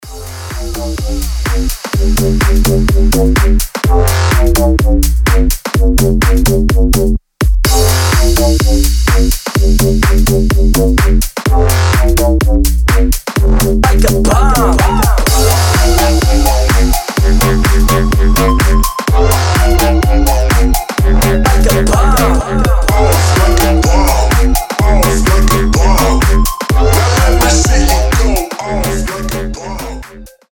• Качество: 320, Stereo
жесткие
EDM
мощные басы
future house
Bass House
качающие
взрывные
Jackin House